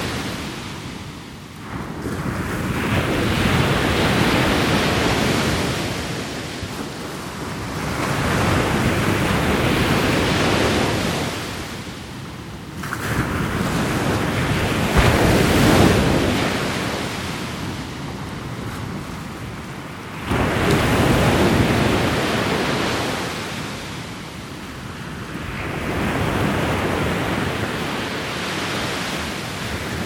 1 -- Sur une plage de sable fin